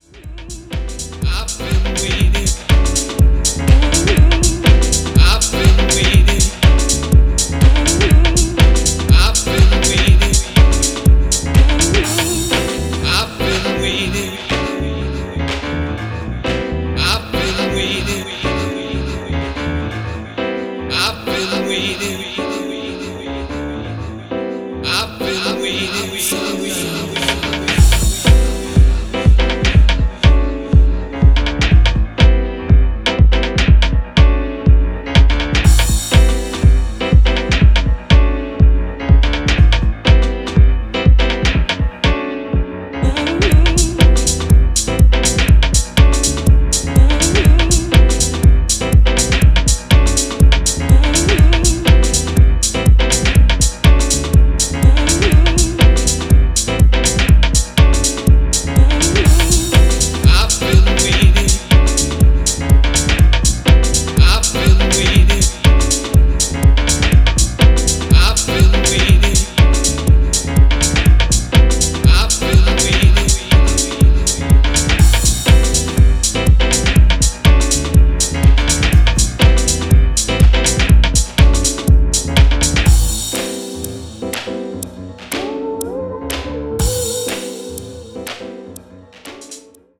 Styl: Disco, House